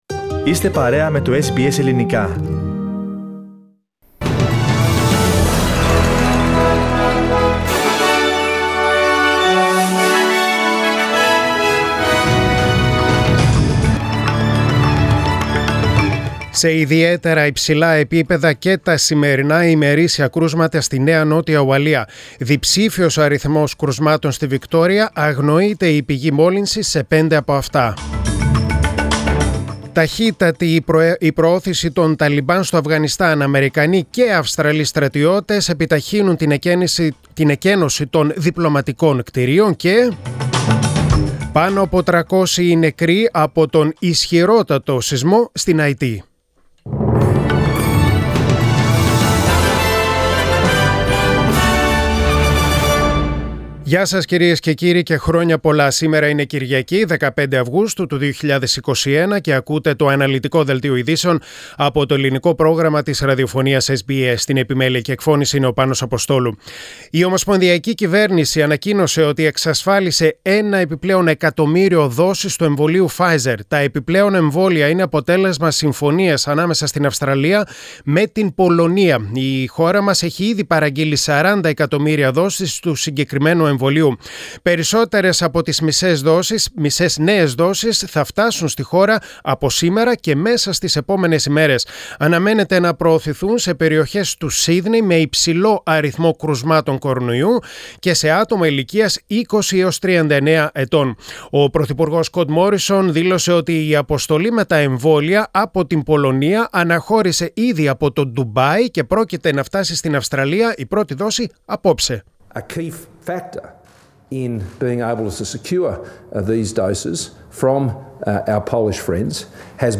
Press Play on the main photo and listen the News Bulletin (in Greek) Share